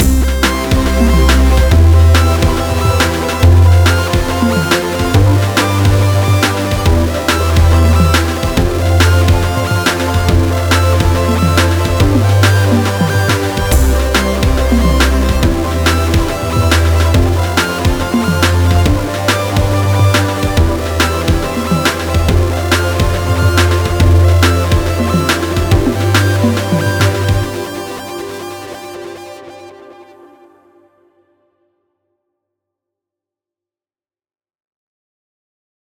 hier ein 30 sec stück (in 5-min. gebastelt) featuring triple cheese, zebralette und podolski...